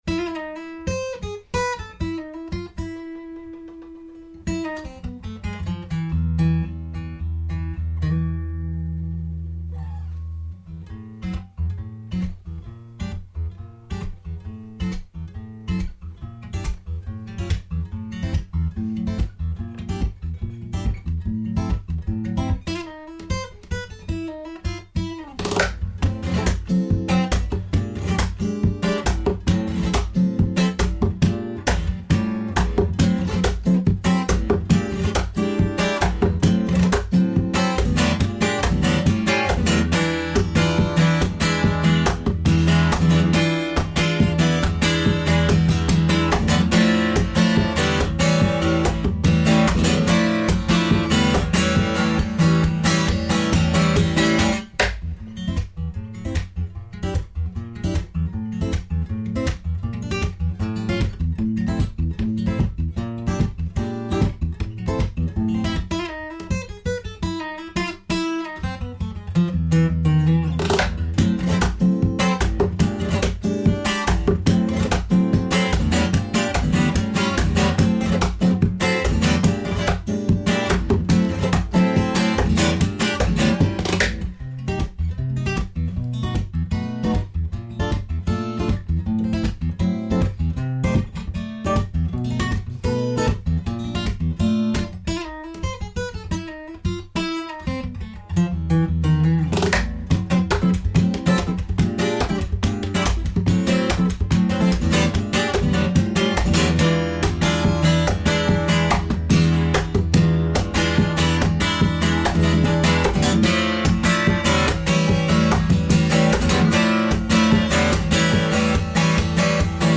Explosive flamenco, Latin, funk & percussive guitarist.